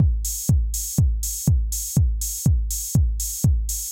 AIR Beat - Mix 5.wav